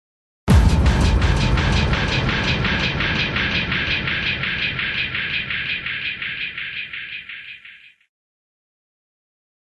Звуки эха
Как услышать настоящее эхо: пример для детей